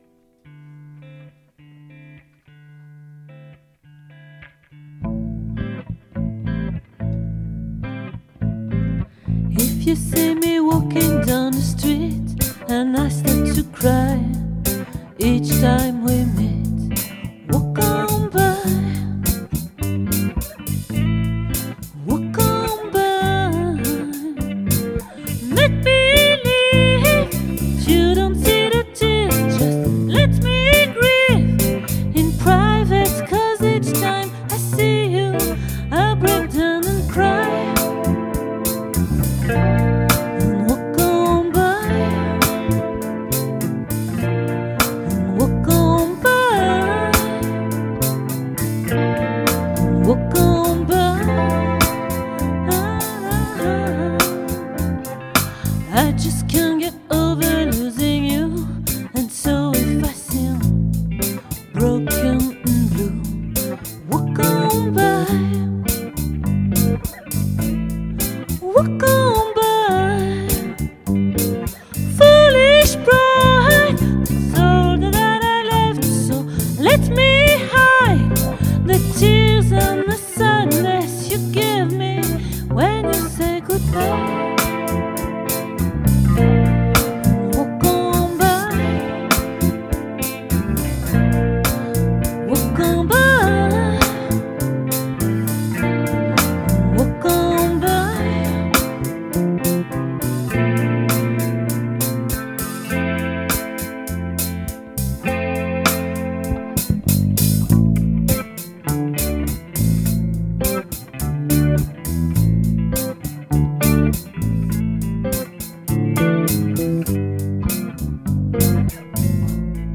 🏠 Accueil Repetitions Records_2022_11_09